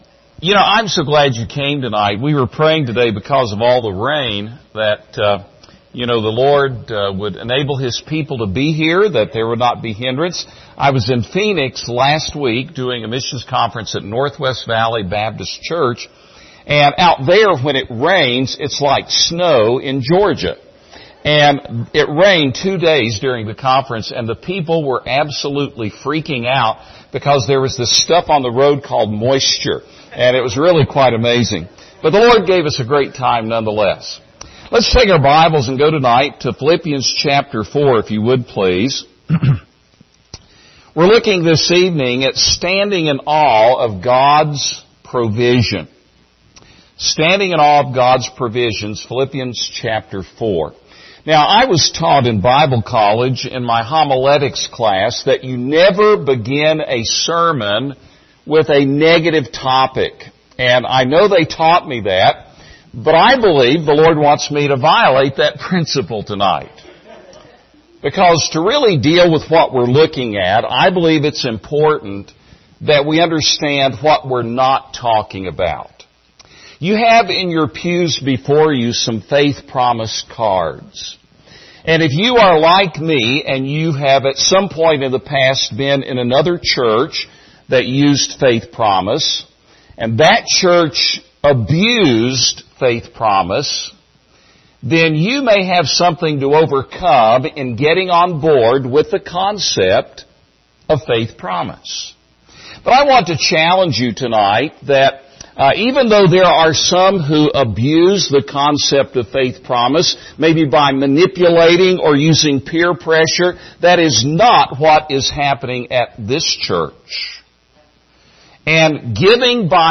Series: 2021 Missions Conference
Service Type: Special Service Topics: Contentment , God's provision